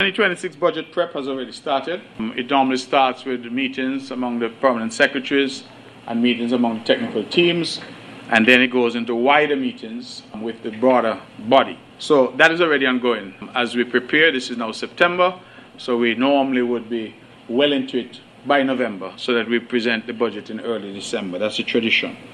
Premier Brantley made the disclosure during his monthly press conference held on September 30th, 2025, noting that early preparation ensures that government departments have enough time to plan and align their priorities.